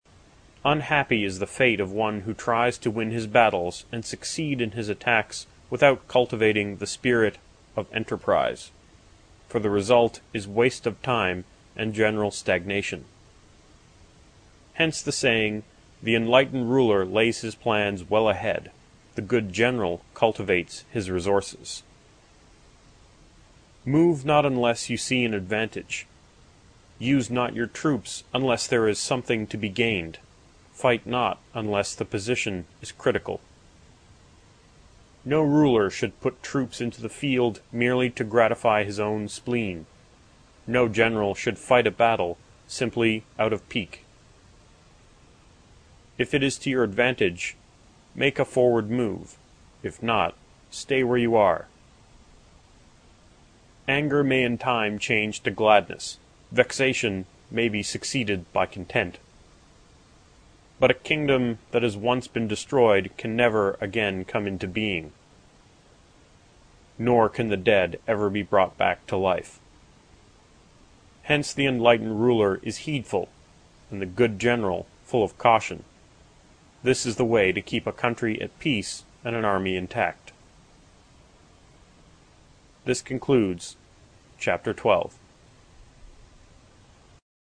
有声读物《孙子兵法》第72期:第十二章 火攻(3) 听力文件下载—在线英语听力室